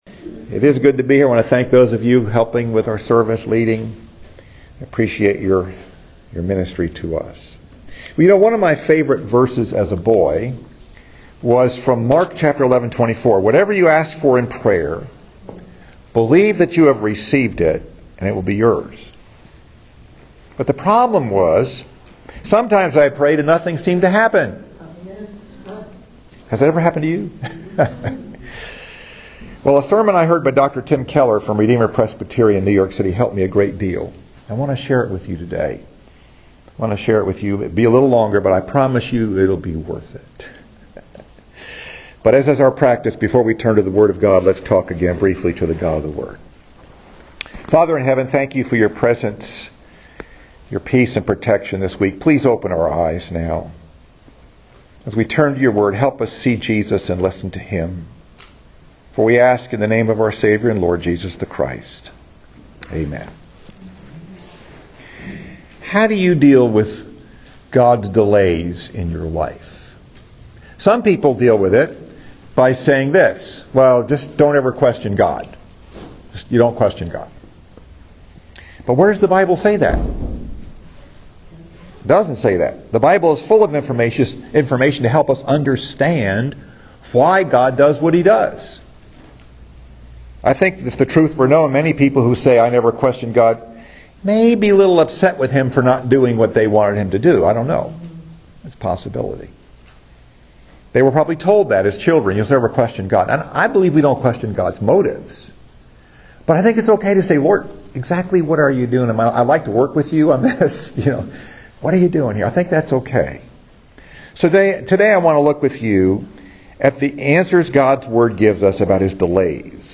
Message: “When God Delays” Scripture: Mark 5: 21-43